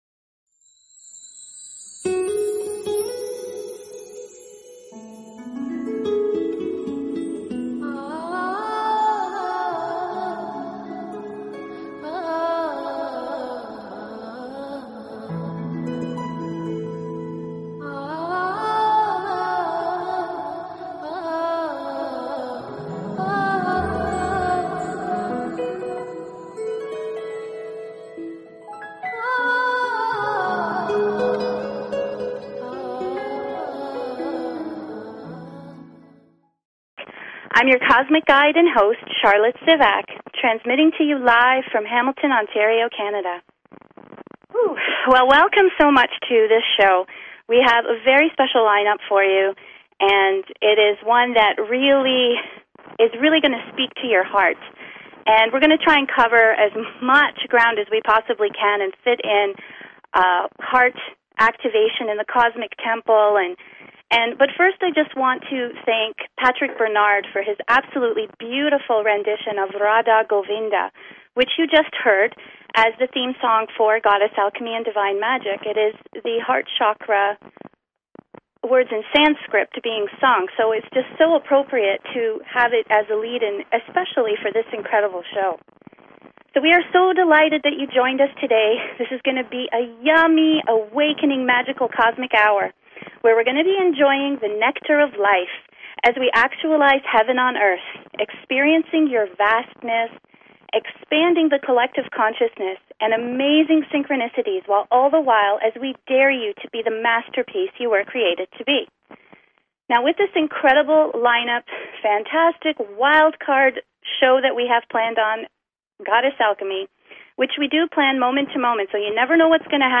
Show 22 of Goddess Alchemy and Divine Magic on BBS Radio!